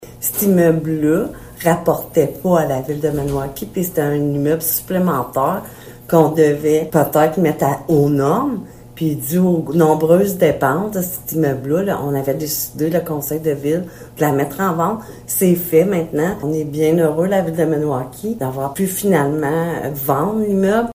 La mairesse de Maniwaki, Francine Fortin, rappelle que le conseil municipal a pris la décision de se départir du bâtiment afin d’assainir les finances de la Ville :